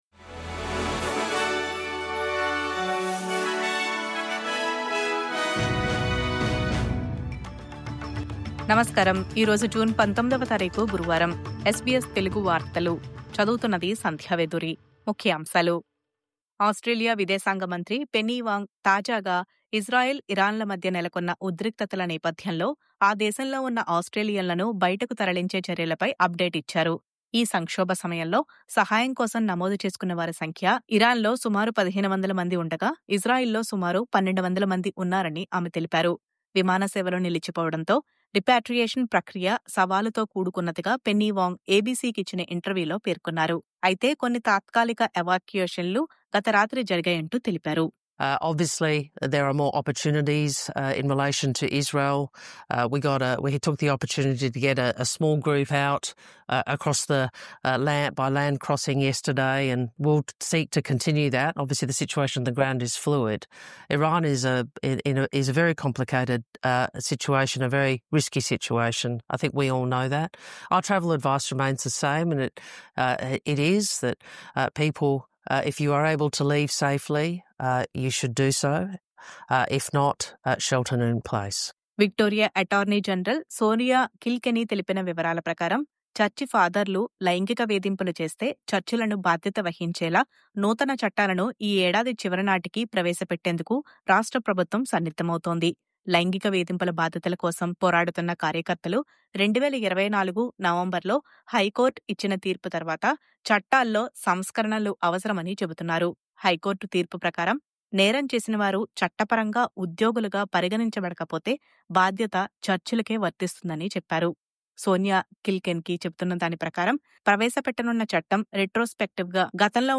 News update: అనైతిక వ్యాపార తీరుపై Optus‌కు ACCC విధించిన $100 మిలియన్ జరిమానా...